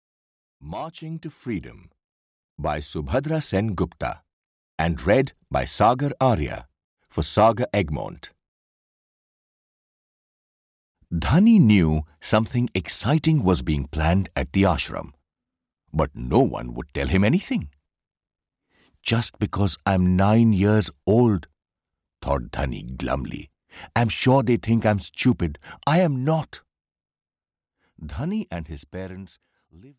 Marching to Freedom (EN) audiokniha
Ukázka z knihy